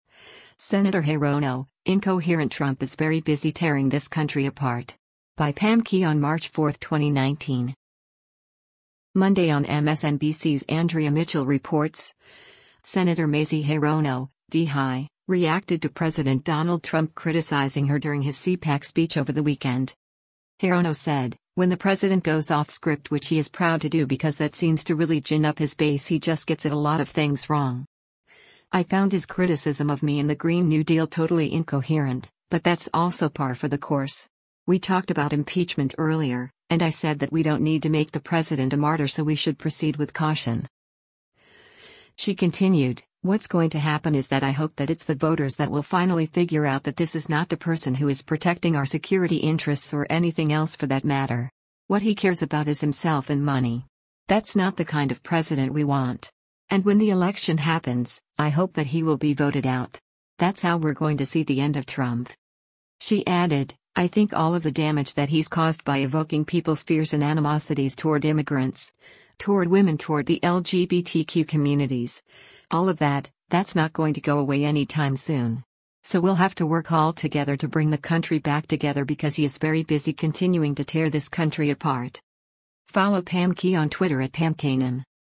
Monday on MSNBC’s “Andrea Mitchell Reports,” Sen. Mazie Hirono (D-HI) reacted to President Donald Trump criticizing her during his CPAC speech over the weekend.